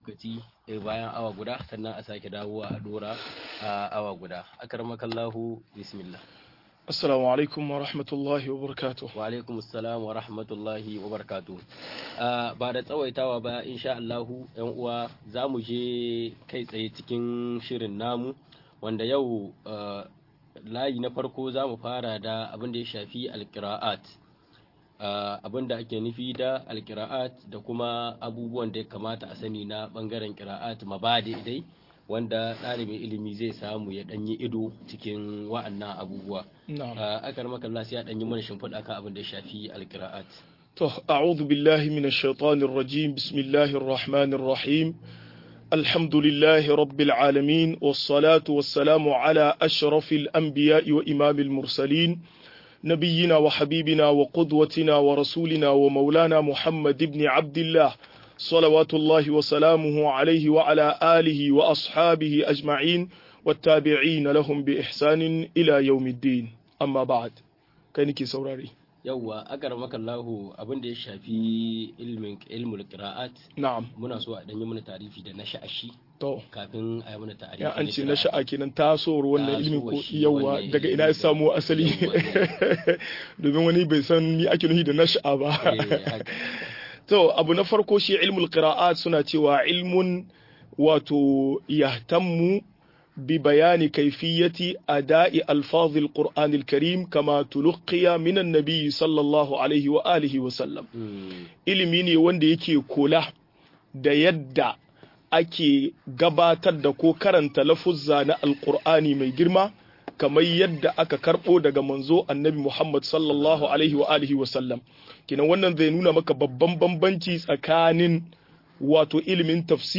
Kira'o'in Alkur'ani - MUHADARA